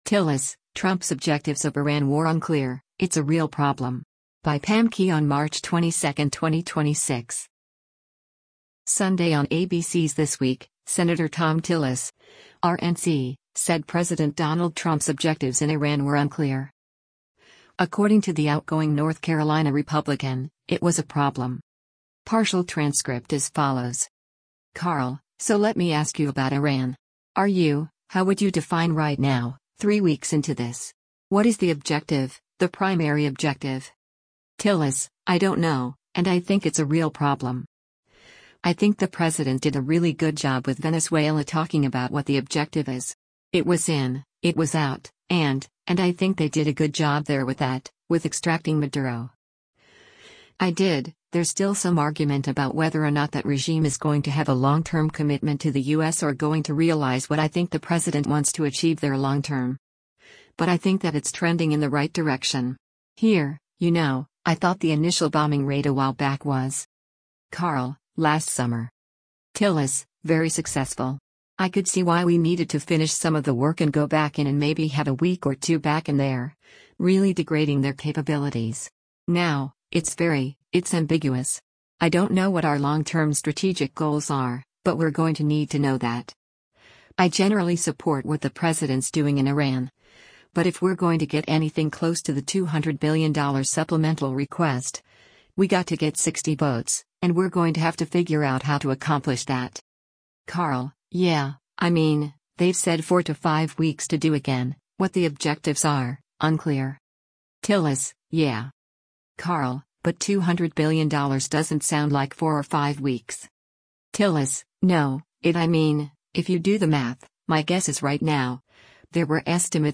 Sunday on ABC’s “This Week,” Sen. Thom Tillis (R-NC) said President Donald Trump’s objectives in Iran were unclear.